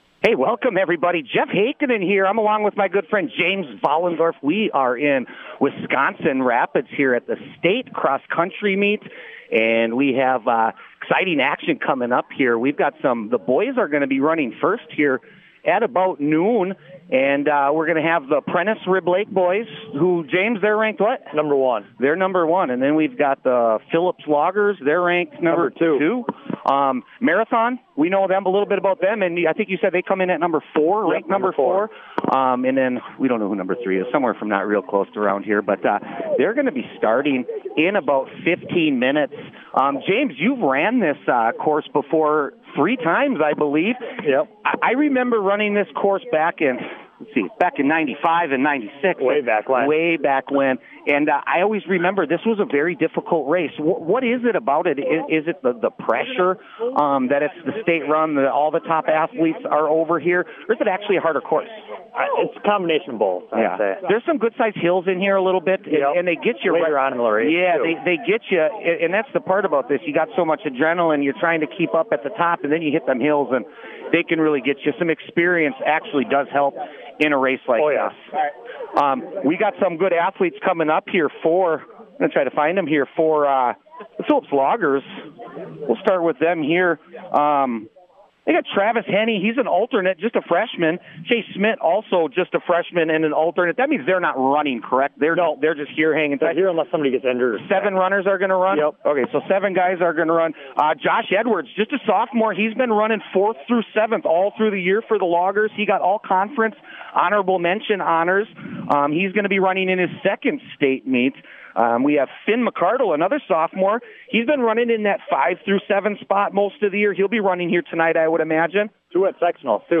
98q interviews